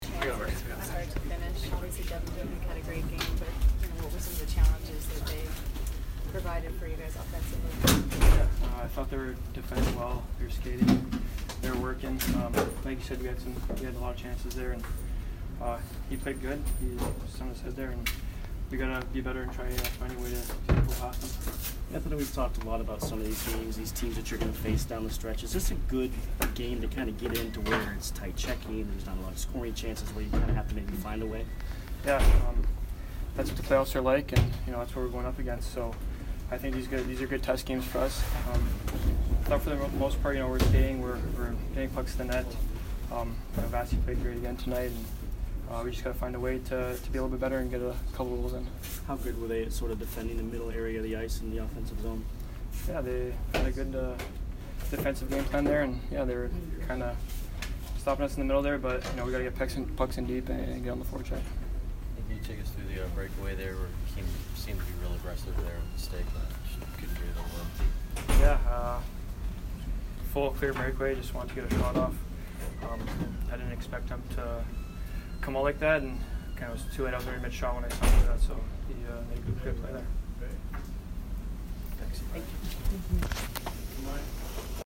Anthony Cirelli post-game 3/7